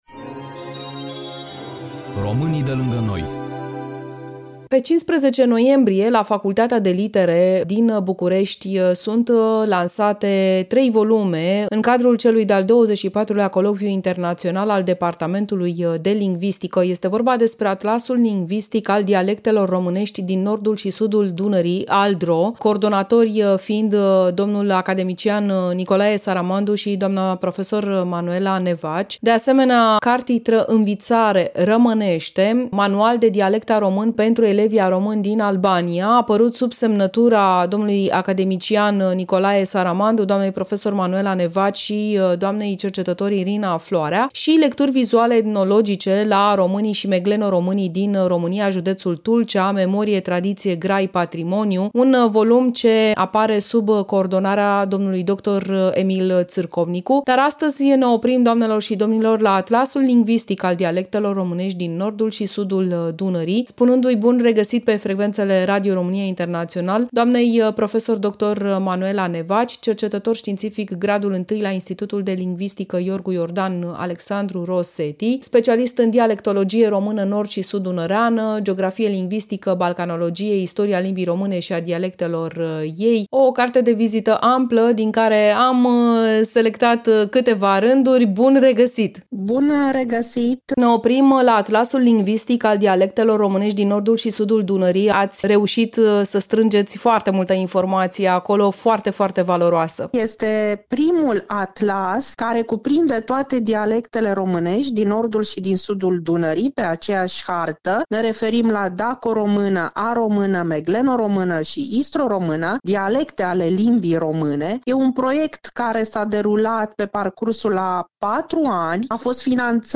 Interviu